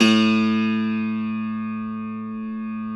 53q-pno05-A0.wav